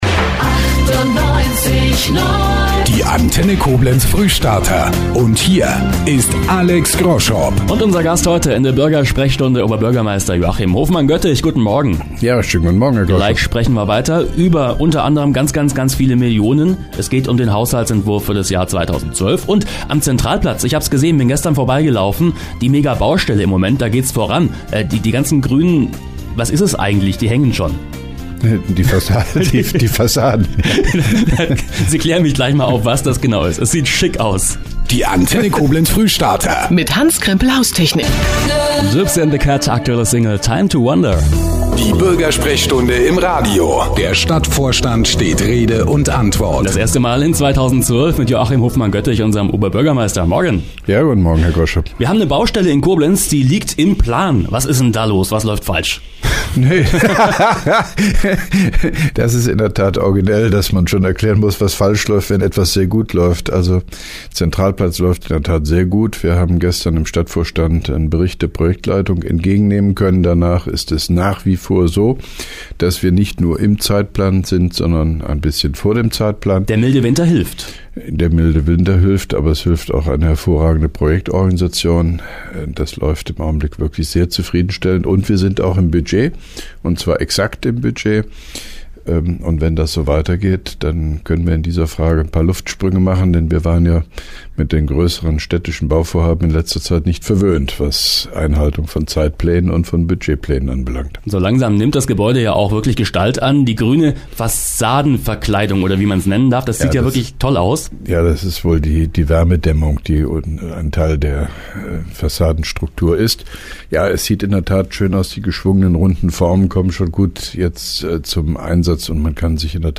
(2) Koblenzer Radio-Bürgersprechstunde mit OB Hofmann-Göttig 17.01.2012